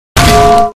Frying Pan Hit Sound Button: Unblocked Meme Soundboard